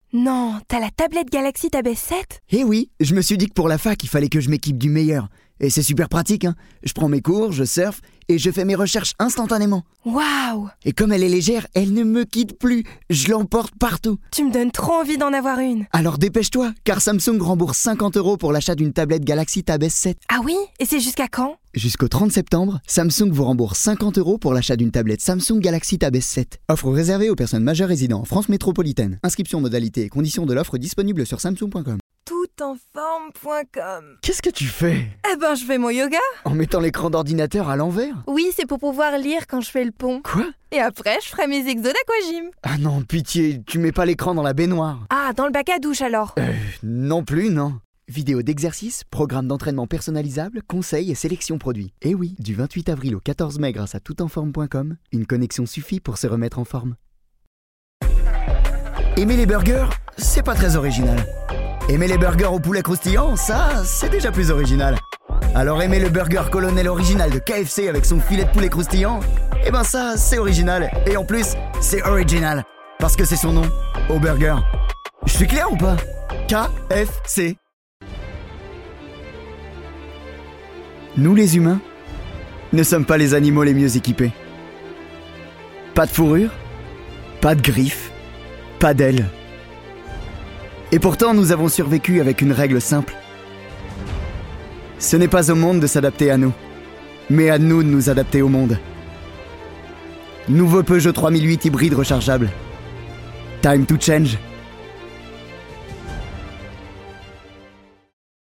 Bandes-son
15 - 40 ans - Baryton